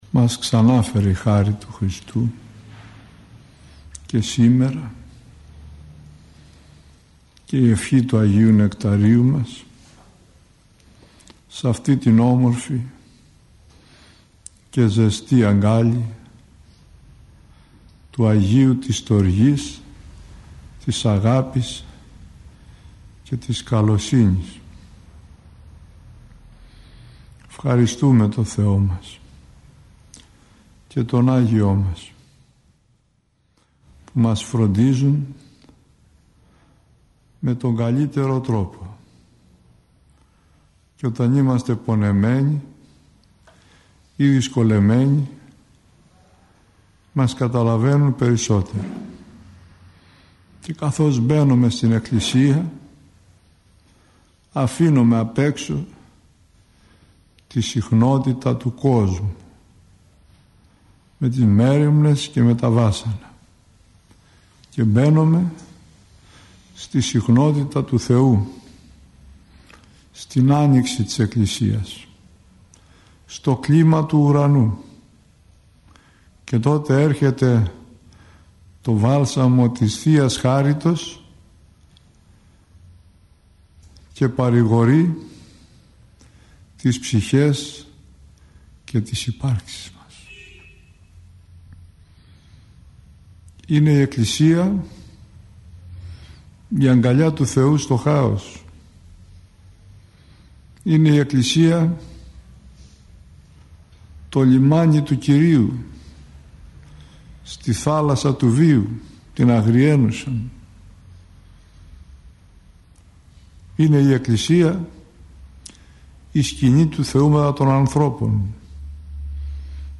Η εν λόγω ομιλία αναμεταδόθηκε από τον ραδιοσταθμό της Εκκλησίας της Ελλάδος.